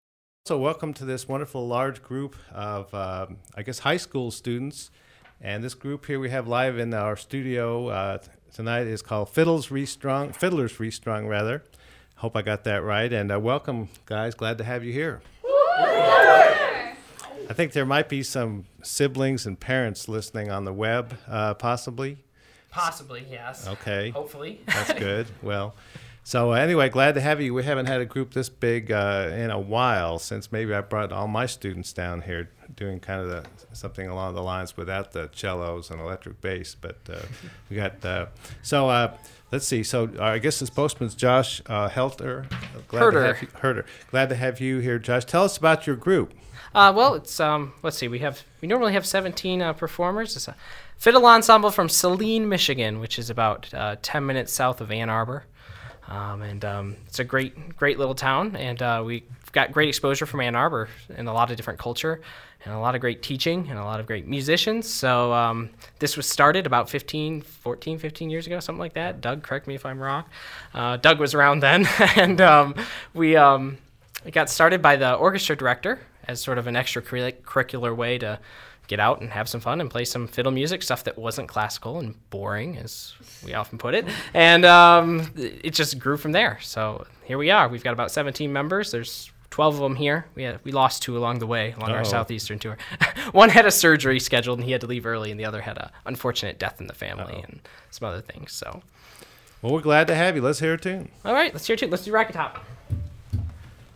Download Music from A Live Performance